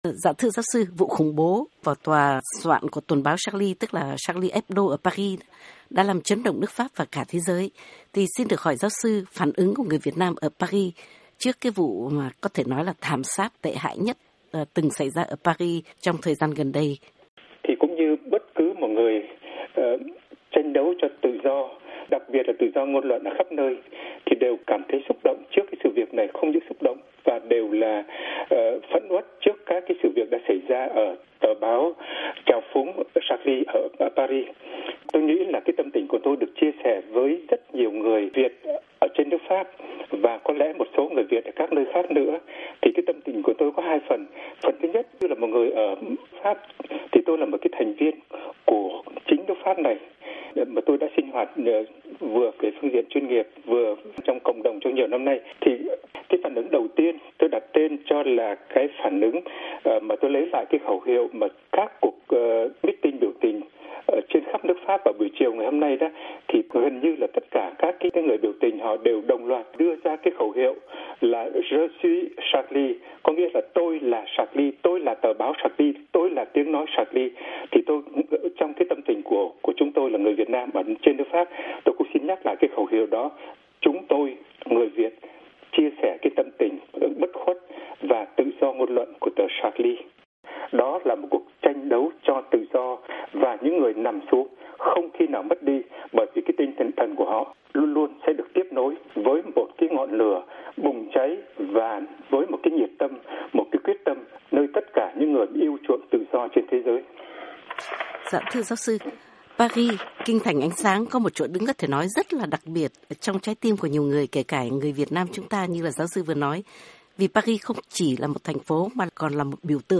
by VOA Tiếng Việt